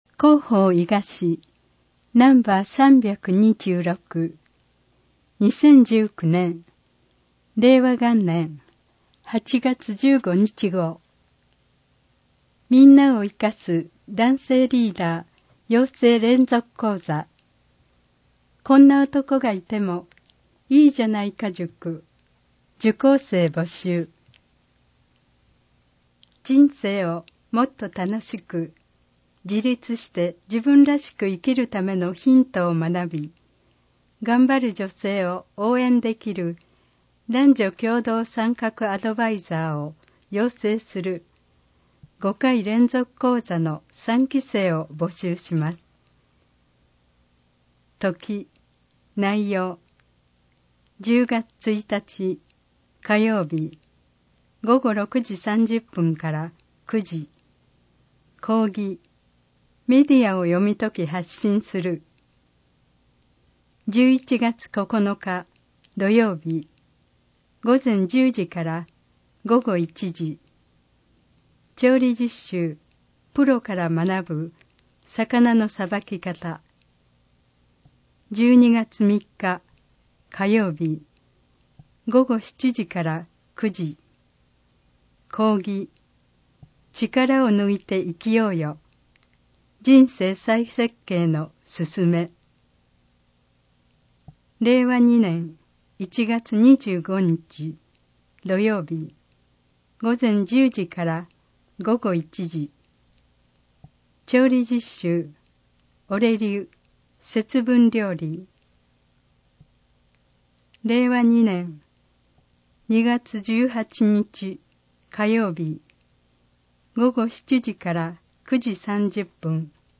音声版